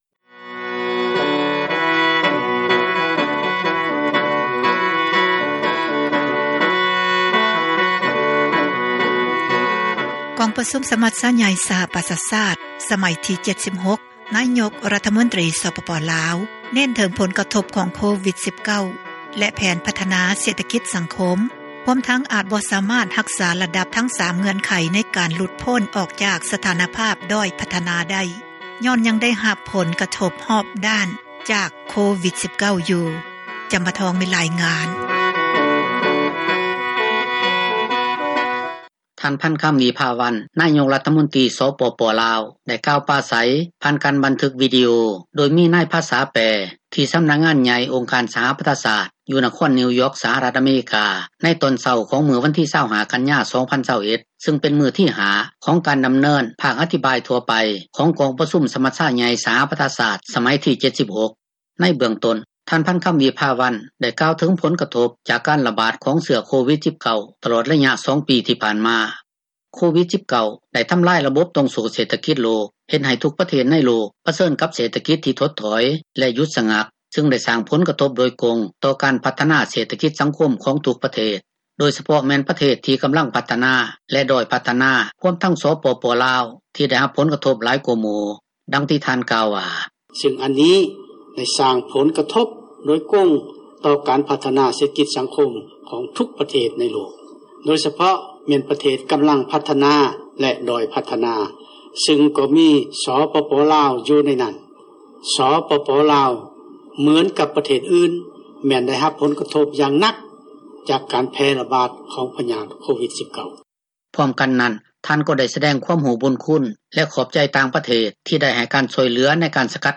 ທ່ານ ພັນຄຳ ວິພາວັນ ນາຍົກຣັຖມົນຕຣີ ສປປລາວ ໄດ້ກ່າວປາໄສ ຜ່ານການບັນທຶກວິດີໂອ ໂດຍມີນາຍພາສາແປ ທີ່ສຳນັກງານໃຫຍ່ ອົງການສະຫະປະຊາຊາດ ຢູ່ນະຄອນນິວຢ໊ອກ ສະຫະຣັຖ ອາເມຣິກາ ໃນຕອນເຊົ້າ ຂອງມື້ວັນທີ 25 ກັນຍາ 2021 ຊຶ່ງເປັນມື້ທີ 5 ຂອງການດຳເນີນ ພາກອະພິປາຍທົ່ວໄປ ຂອງກອງປະຊຸມສະມັດຊາໃຫຍ່ສະຫະປະຊາຊາດ ສະໄໝທີ 76.